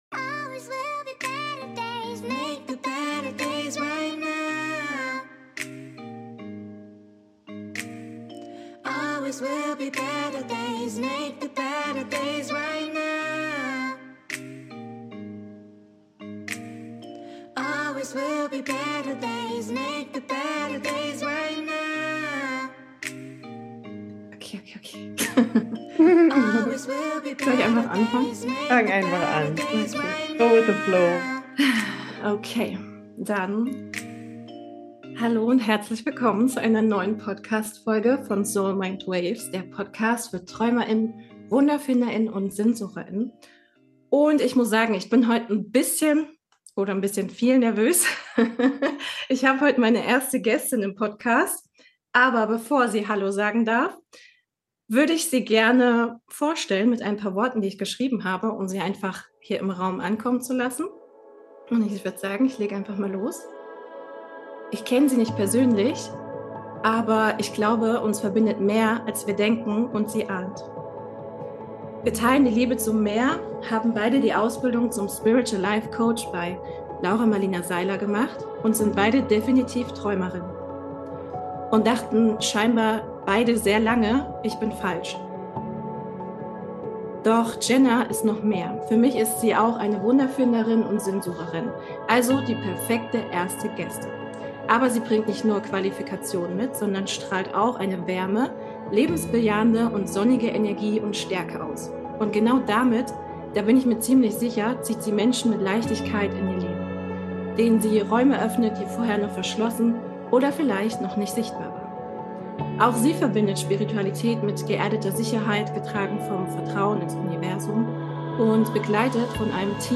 Hier treffen zwei Visionärinnen aufeinander, die sich noch nie trafen aber schon mindestens eine Synchronizität miteinander teilen.